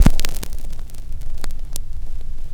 cch_fx_noiz.wav